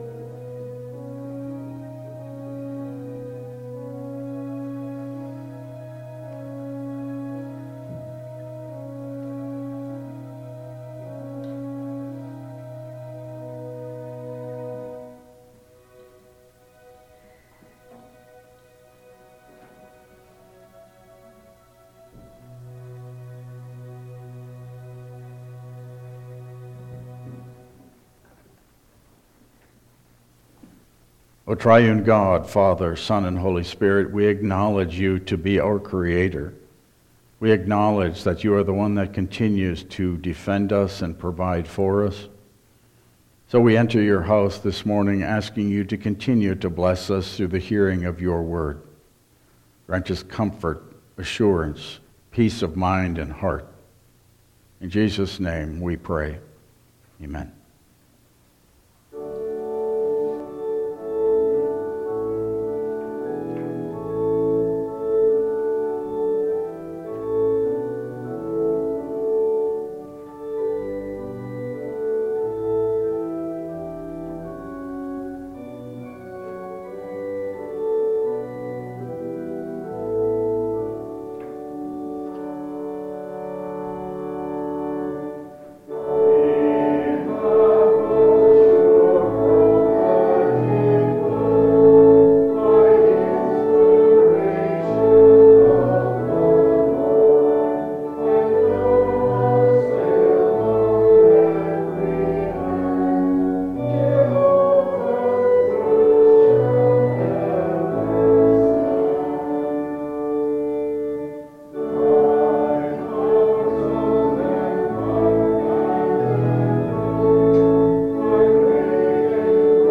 Passage: Matthew 18:1-10 Service Type: Regular Service